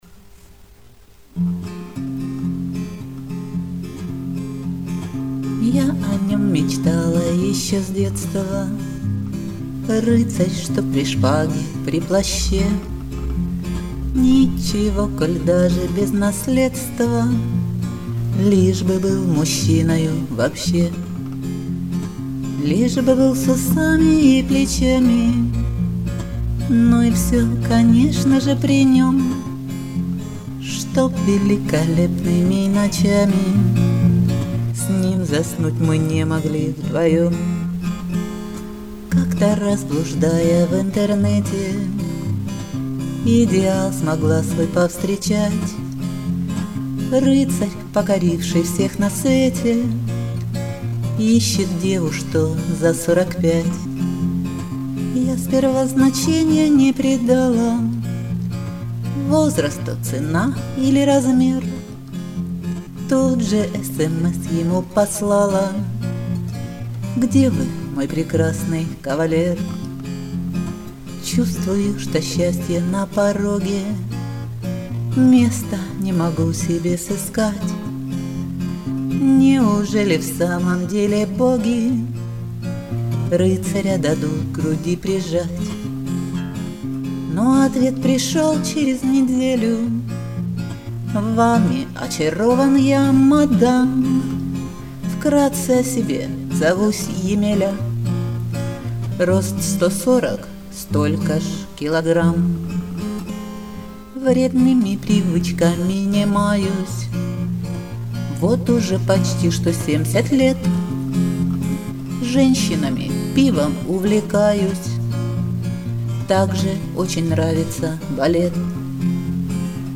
Шансон
Записала их по-памяти в домашних условиях.